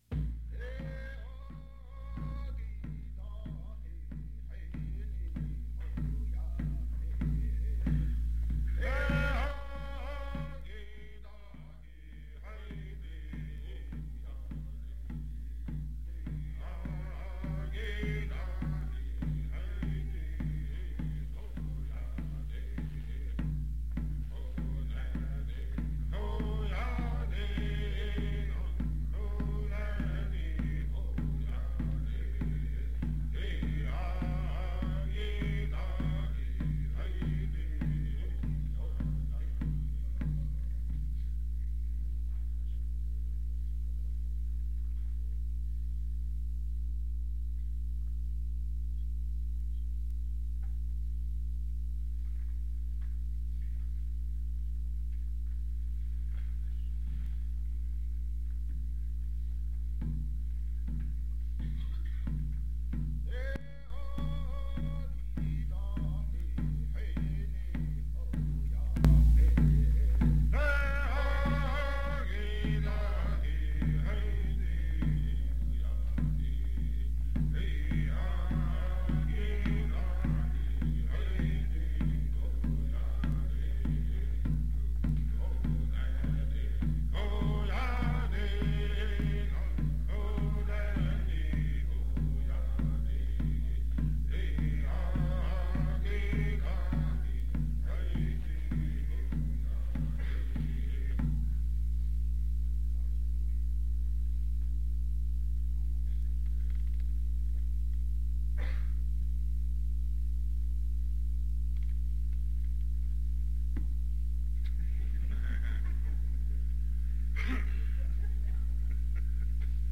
Lead singer with drum
OLAC Discourse Type singing
Place Cattaraugus Indian Reservation (N.Y.)